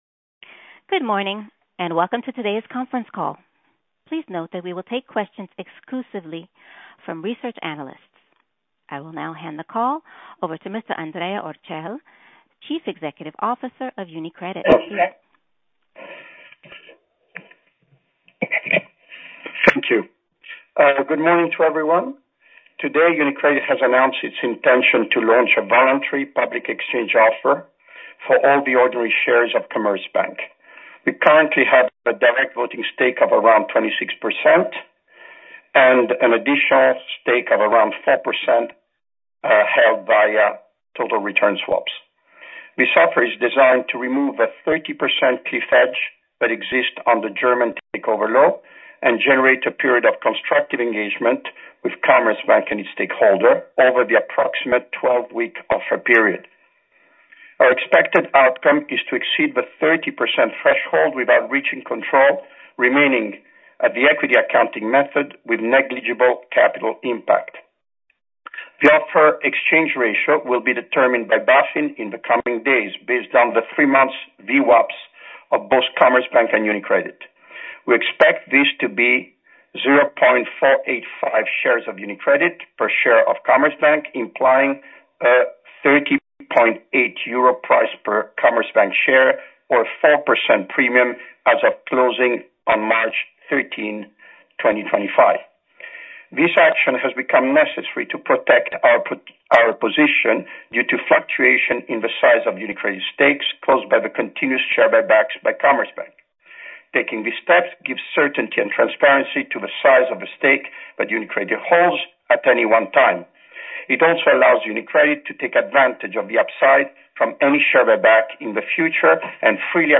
• Audioaufzeichnung der Telefonkonferenz vom 16. März 2026